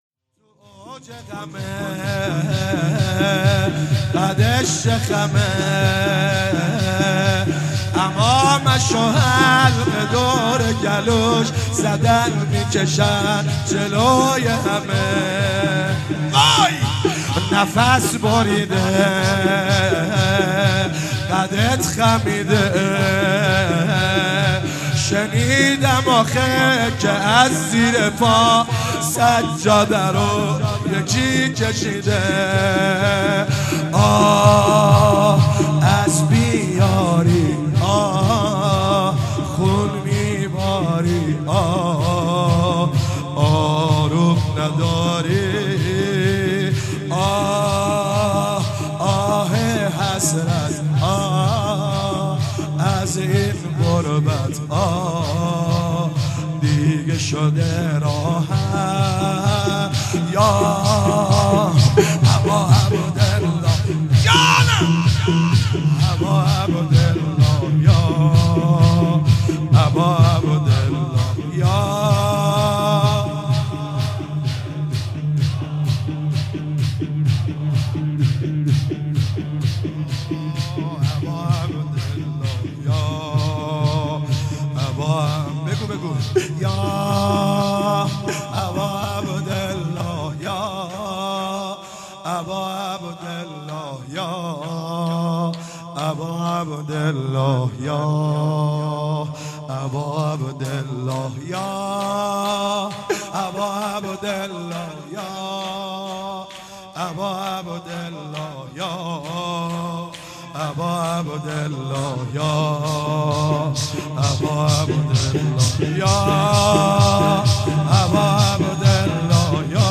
شهادت امام صادق شب اول 96 - زمینه - تو اوج غمت قدش چه خمه
زمینه مداحی